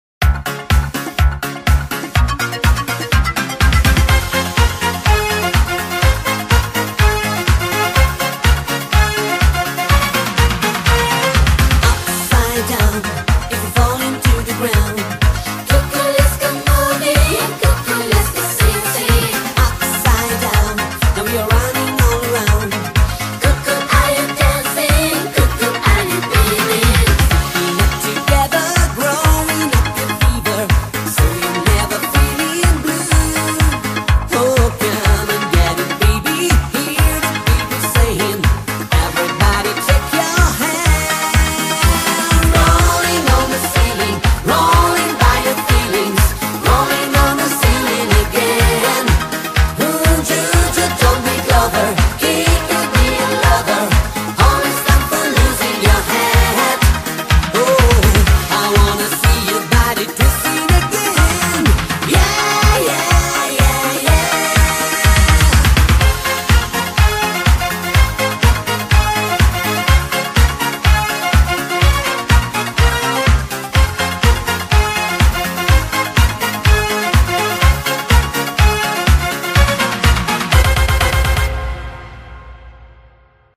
BPM124